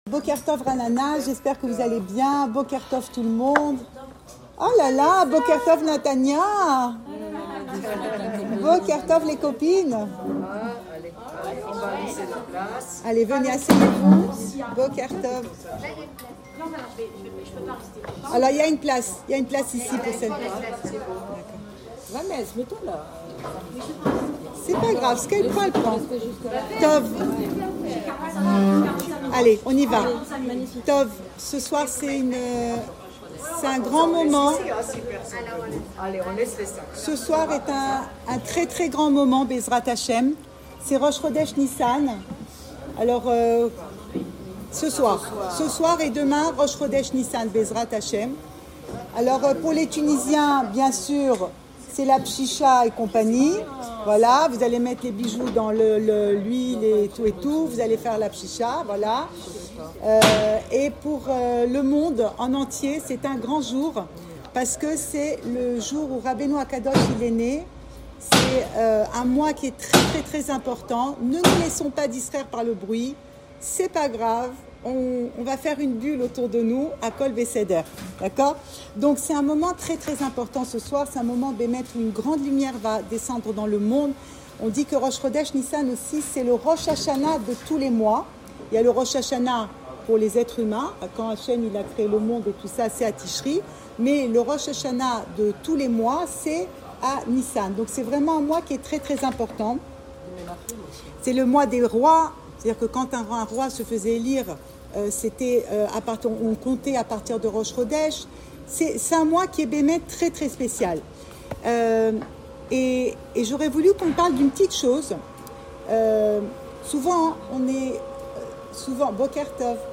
Cours audio Le coin des femmes Le fil de l'info Pensée Breslev - 18 mars 2026 18 mars 2026 Le secret de Rabbenou. Enregistré à Raanana